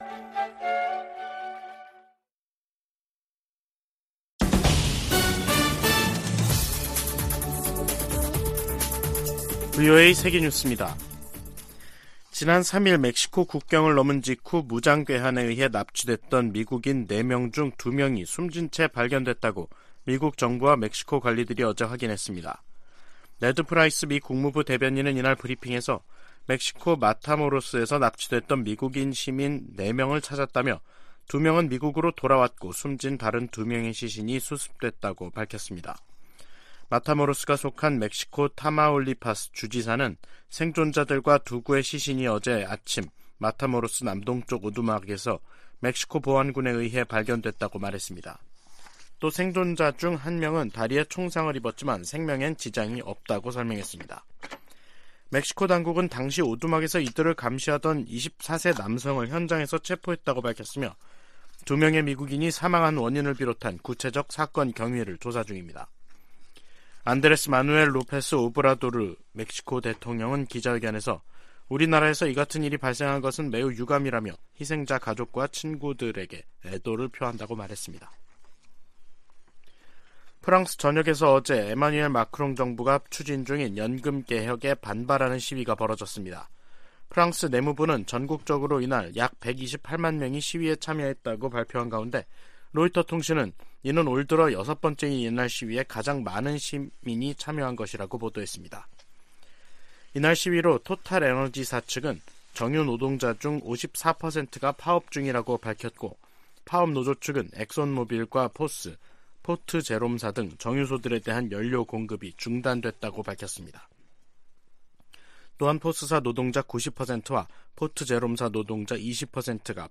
VOA 한국어 간판 뉴스 프로그램 '뉴스 투데이', 2023년 3월 8일 2부 방송입니다. 백악관은 윤석열 한국 대통령이 다음 달 26일 미국을 국빈 방문한다고 밝혔습니다. 미국 사이버사령관이 미국 정치에 개입하려 시도하는 상위 4개국으로 북한과 중국, 러시아, 이란을 꼽았습니다. 한국은 전시작전통제권 전환을 위한 일부 역량을 키웠지만 연합방위를 주도할 능력을 입증해야 한다고 전 주한미군사령관이 지적했습니다.